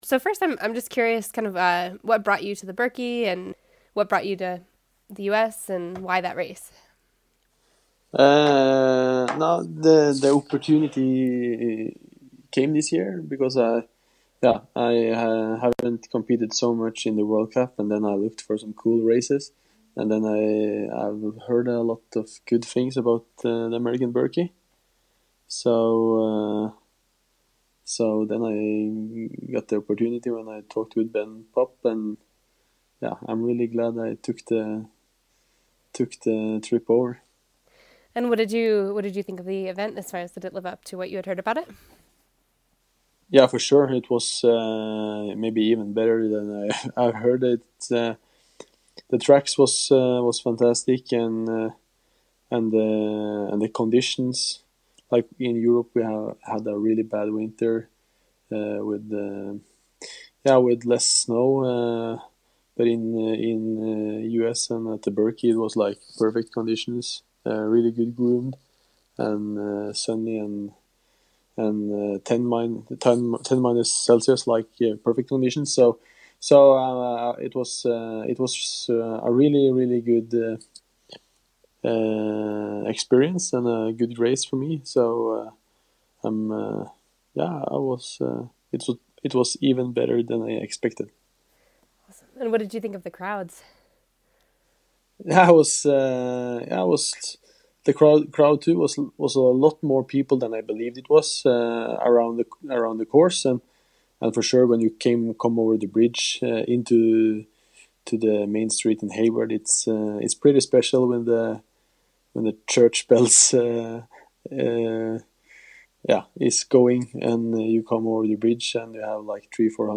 In a post-race conversation after returning to Norway, Dyrhaug explained that the American Birkie has been on his radar for some time, and he was excited the opportunity arose this year. He also shared his thoughts on the quality of the event and competition, and of course the feverish Birkie crowds.
Post-Birkie interview with Niklas Dyrhaug.